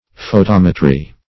Photometry \Pho*tom"e*try\, n. [Cf. F. photom['e]trie.]